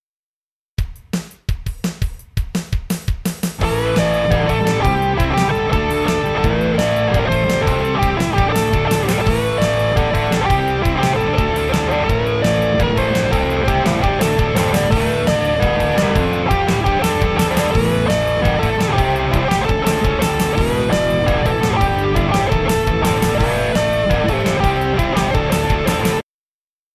So, off to the groovy cupboard we went, where I pulled out the Gibson Les Paul Special, plugged it into a practice amp and widdled away a few riffs..
A guitar, with twin P90s that
snarl and bark like a Rottweiler let loose in the Post Office canteen at lunchtime, not "Rawk" enough?
A guitar that pretty much gives the Pete Townshend "Live at Leeds" sound, not "Rawk" enough?